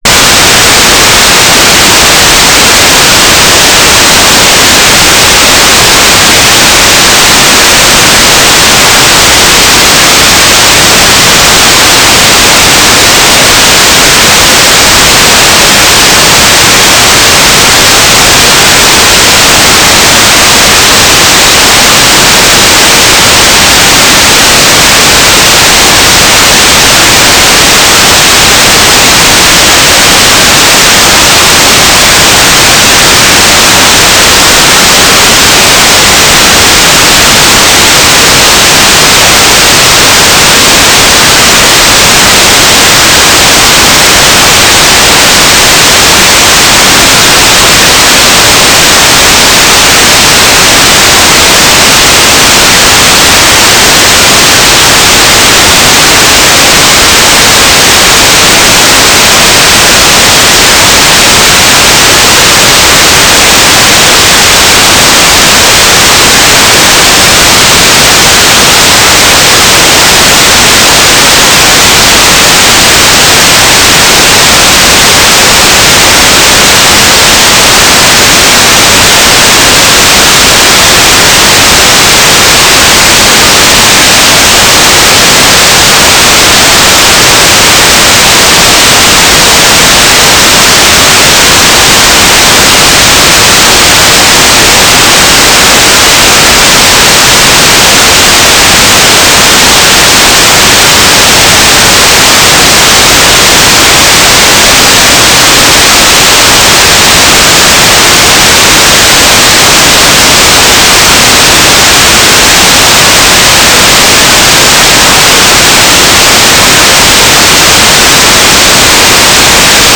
"transmitter_description": "9k6 FSK TLM",
"transmitter_mode": "FSK",